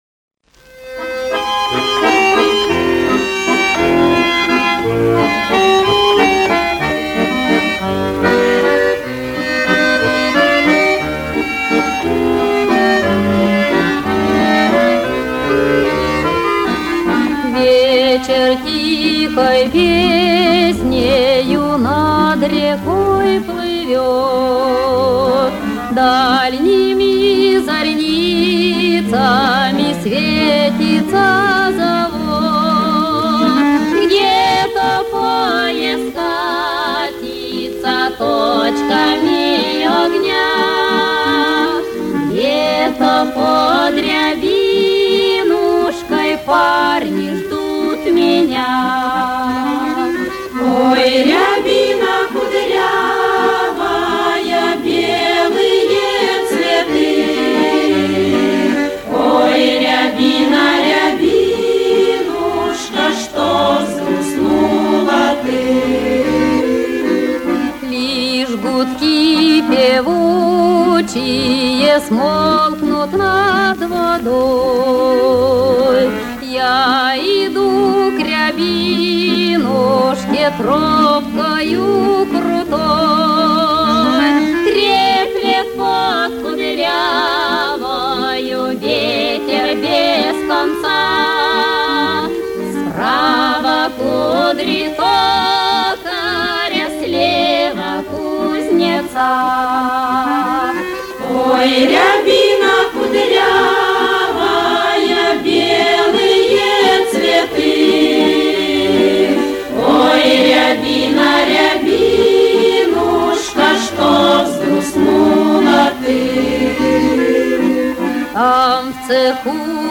Можно сравнить запись с пластинки 1955 года
Каталожная категория: Хор |
Жанр: Песня
Место записи:    Москва |